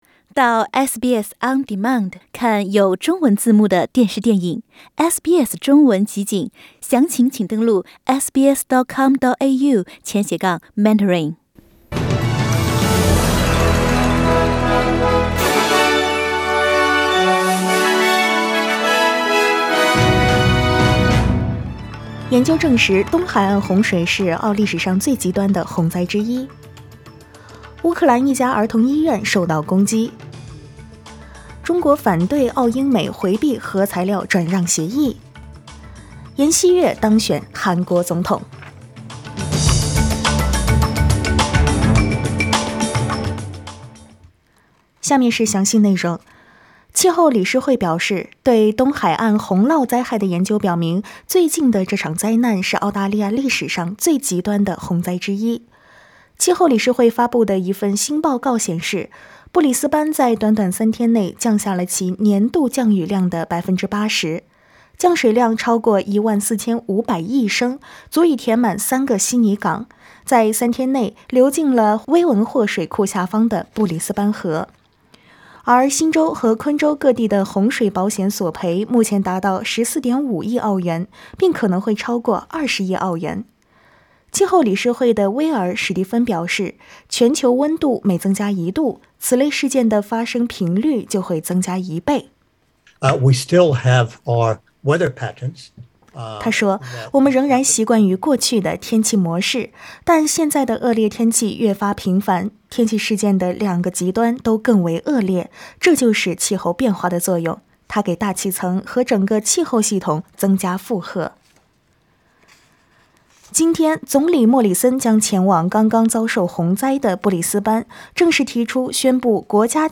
SBS早新聞（3月10日）
SBS Mandarin morning news Source: Getty Images